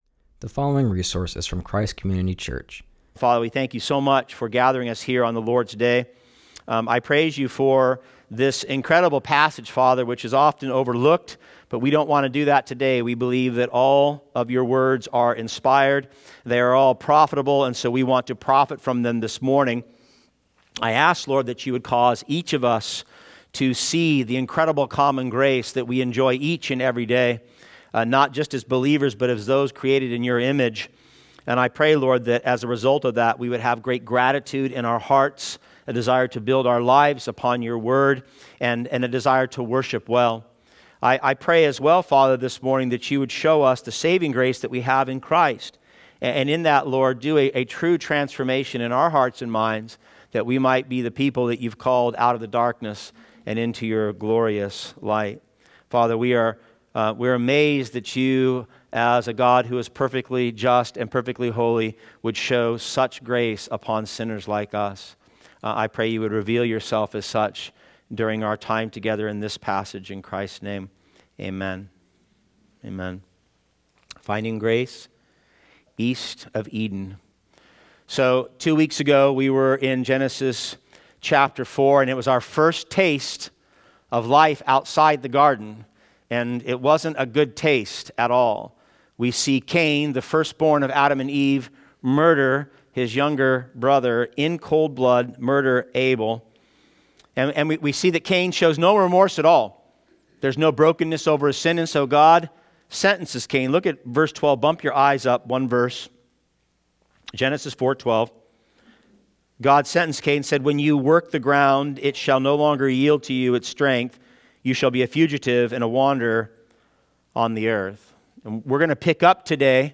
continues our series and preaches from Genesis 4:13-26.